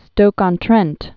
(stōkŏn-trĕnt, -ôn-)